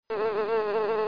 La mouche | Université populaire de la biosphère
elle bourdonne
mouche.mp3